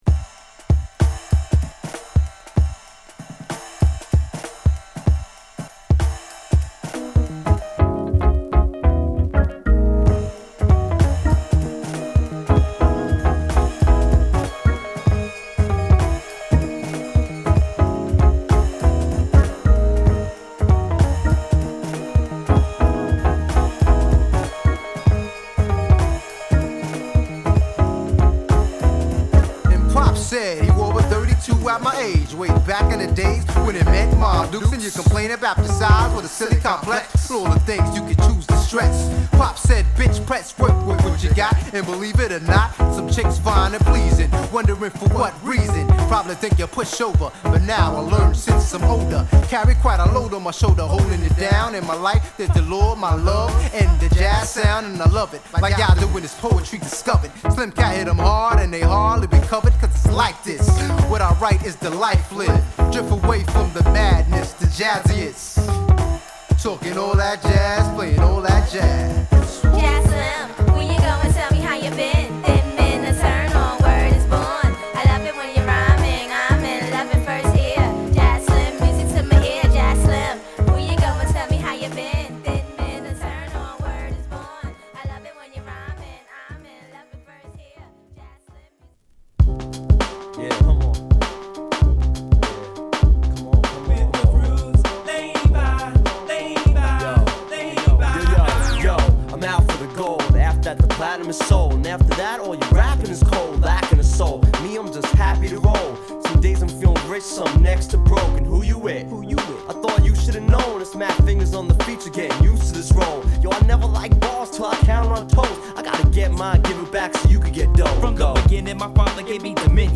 オルガンのループに前のめりなドラム／リズムが絡むトラックでラップ！